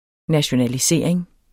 Udtale [ naɕonaliˈseˀʁeŋ ]